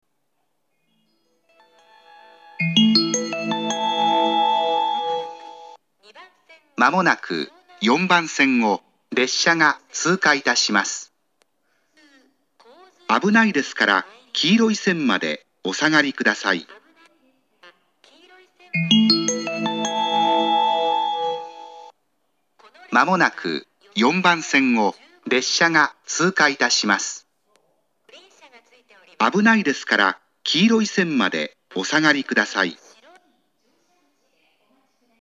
４番線通過列車接近放送